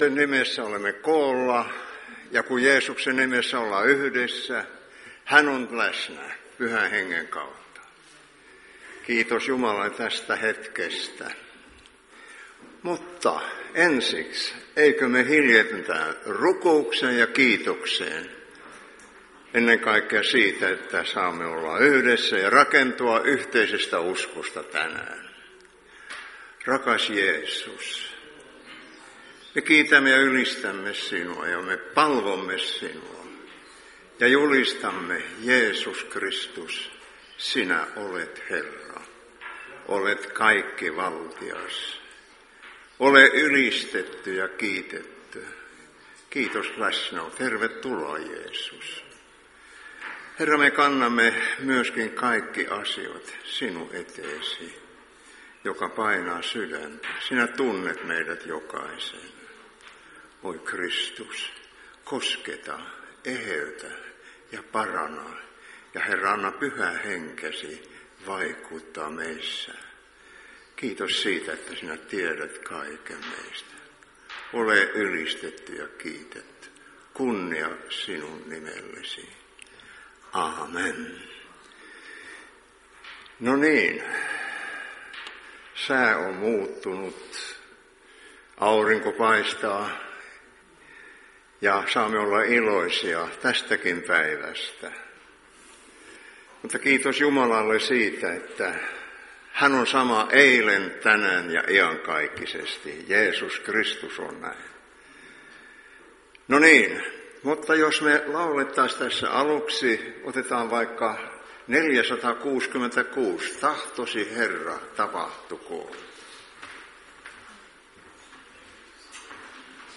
Iltapäiväkokous 26.2.2023